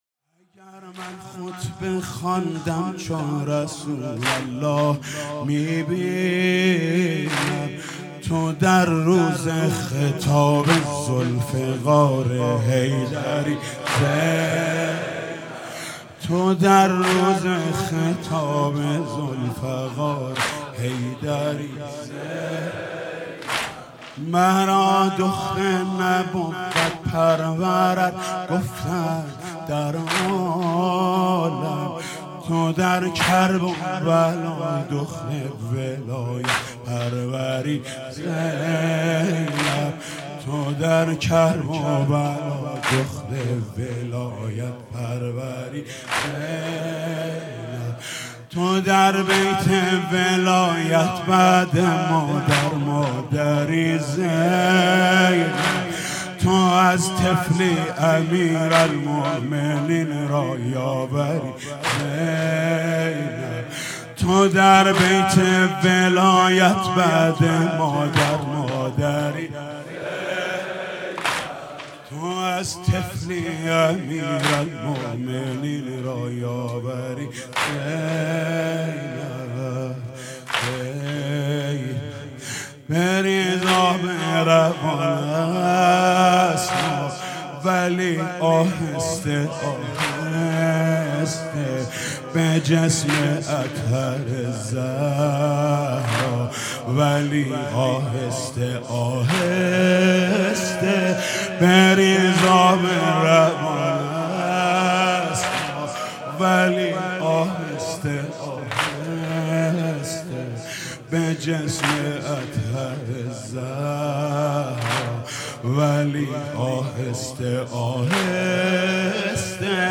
مداحی و نوحه
سینه زنی در شهادت حضرت فاطمه زهرا(س)